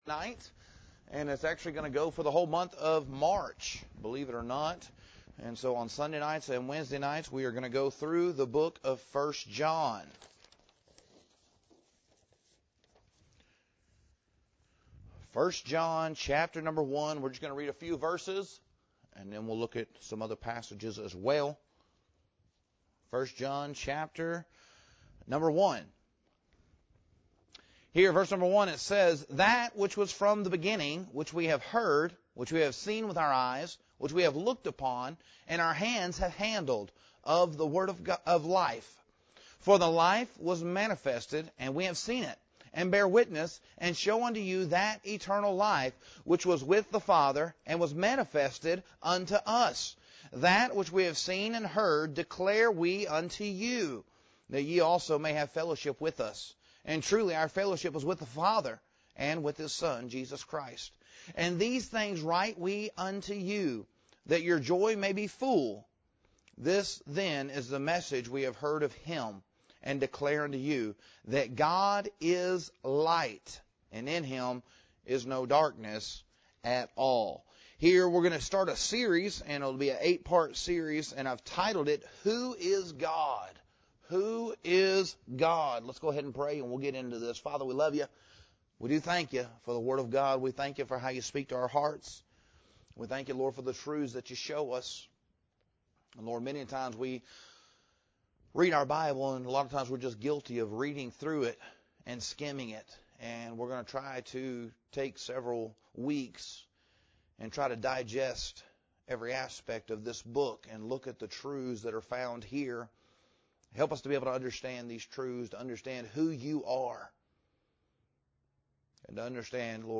This sermon begins in the clear and compelling opening of First John chapter one.